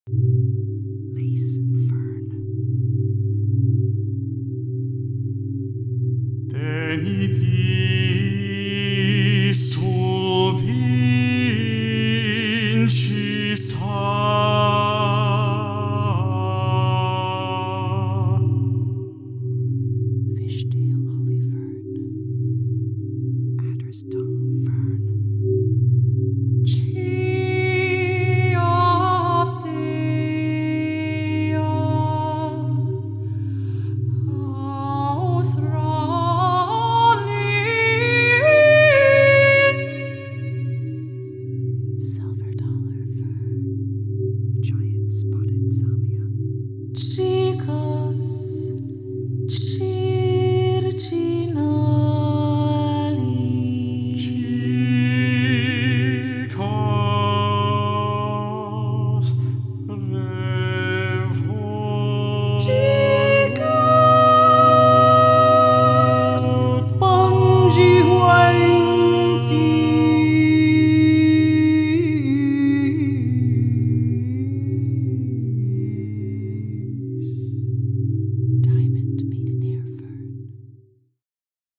Four-channel sound installation